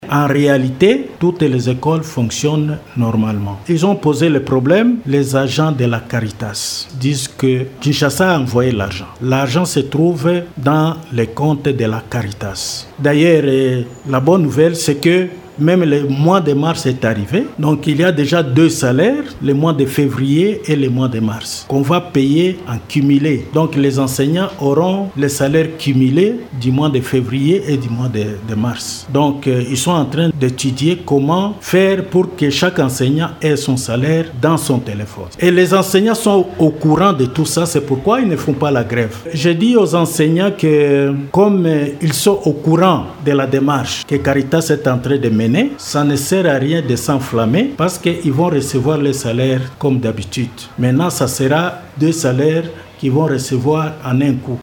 Cet appel est du Directeur Provincial ad intérim de l’éducation Nationale et nouvelle citoyenneté Sud-Kivu 1 Léon MUSAGI MUKAMBILWA vendredi 28 mars 2025 dans un entretien avec Radio Maendeleo faisant suite aux informations faisant état d’une grève qui aurait été déclenchée par certains enseignants de cette sous division.